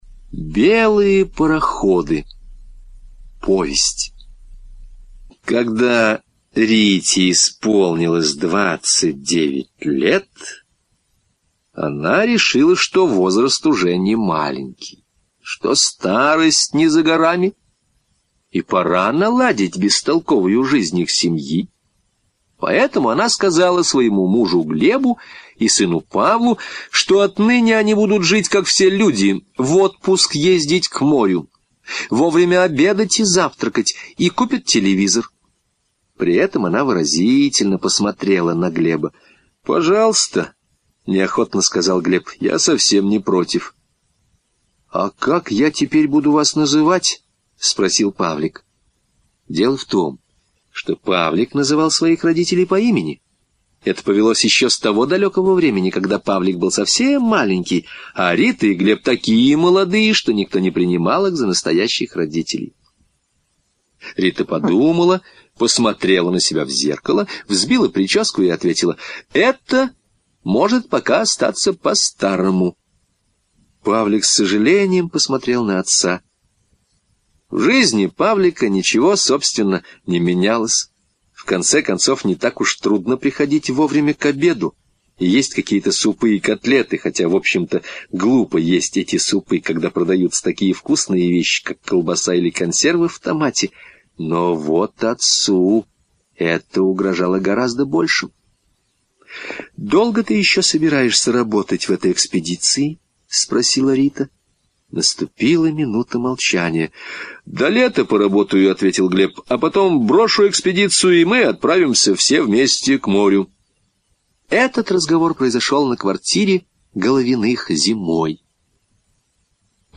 Белые пароходы — слушать аудиосказку Владимир Железников бесплатно онлайн